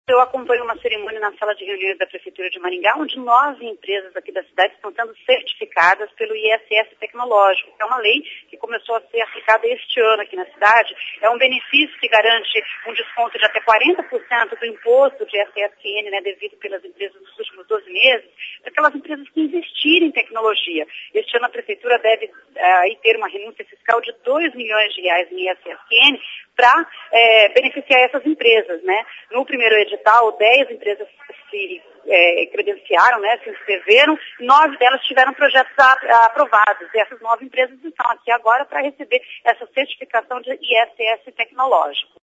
Empresas certificadas L.P 30-04 AO VIVO.mp3